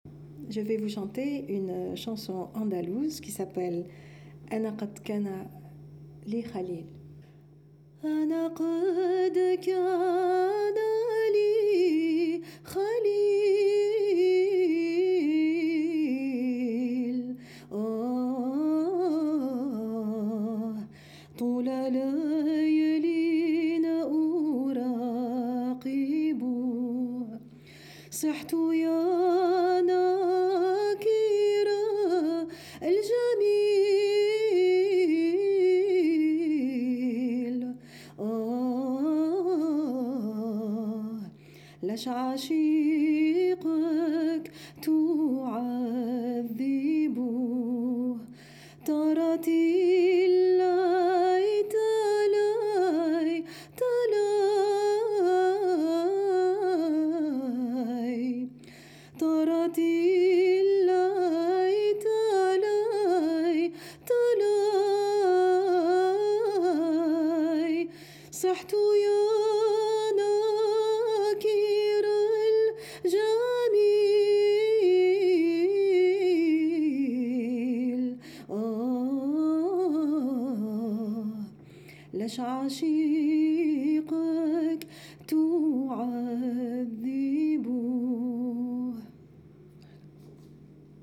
Chants, berceuses, paysages sonores... récoltés dans nos itinérances.